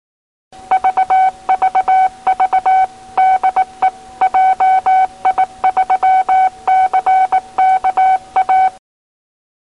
☆ＣＷ信号モニタ音
eisei-cwtone-vvvcka.mp3